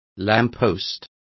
Complete with pronunciation of the translation of lampposts.